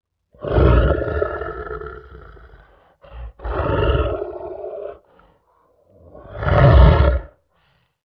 clap-sound